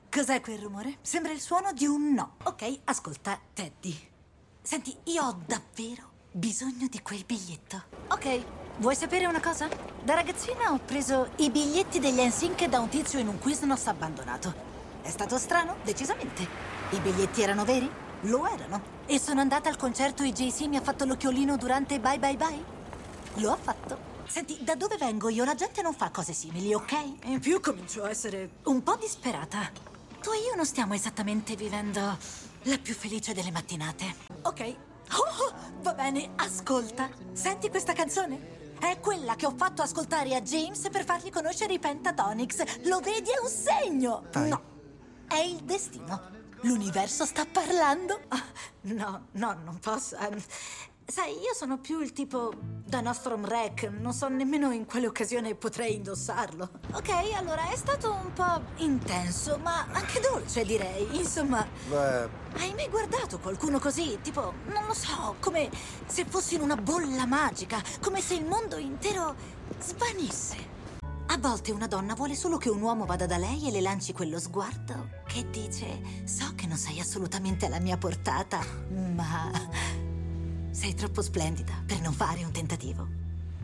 FILM CINEMA